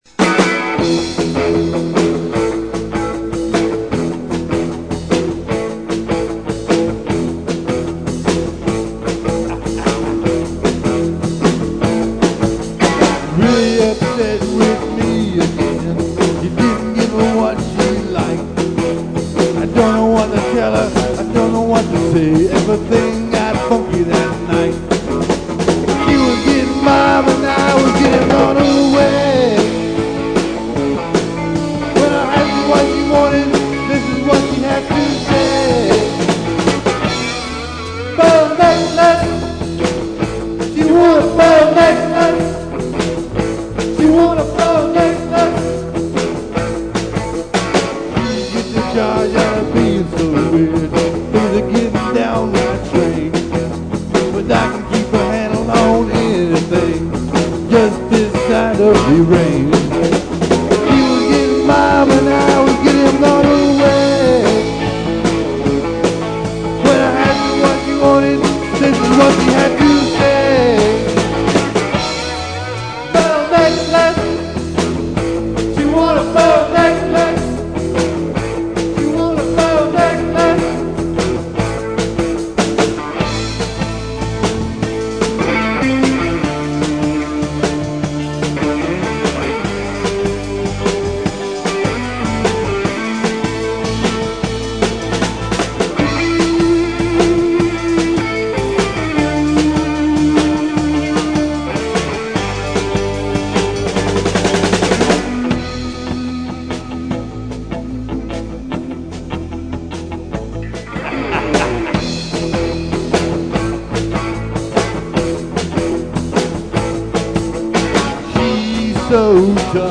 ROCK 'N ROLL
Live At L. C. Saloon 1/16/83 + Bonus Tracks